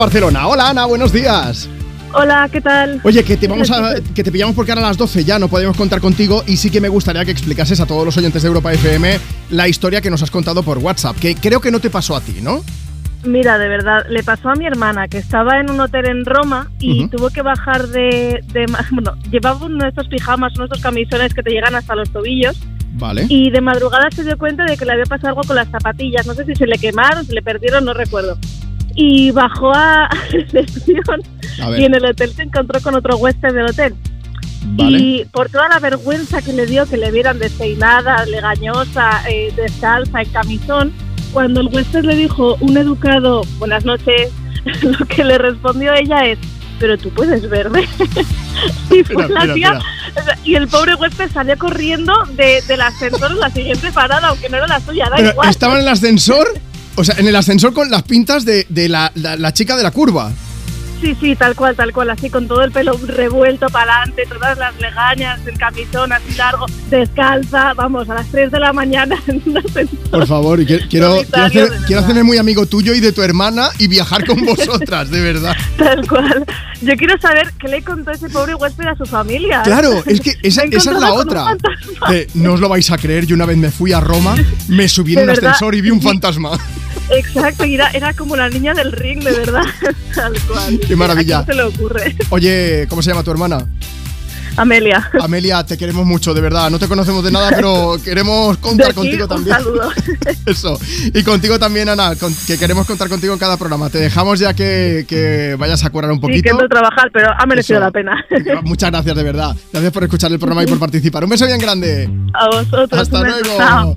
una oyente de Me Pones